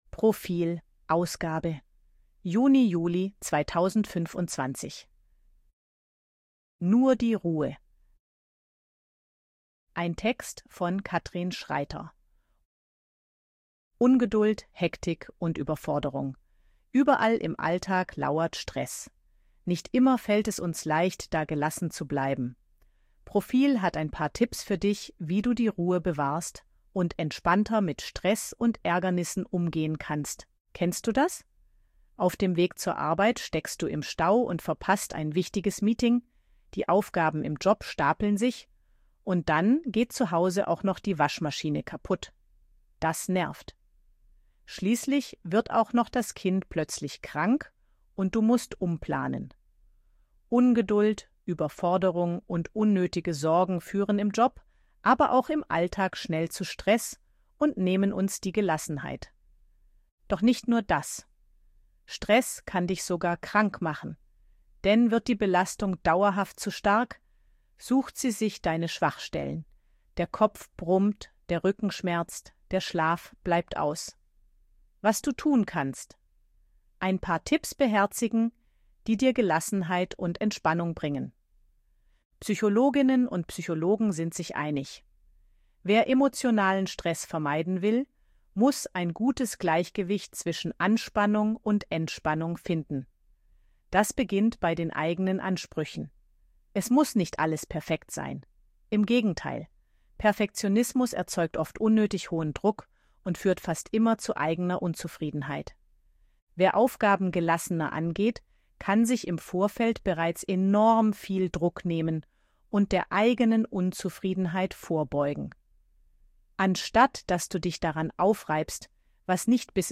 Artikel von KI vorlesen lassen
ElevenLabs_KI_Stimme_Frau_Service_Leben.ogg